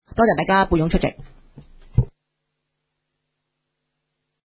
委员会会议的录音记录
地点: 香港湾仔轩尼诗道130号修顿中心21楼 湾仔区议会会议室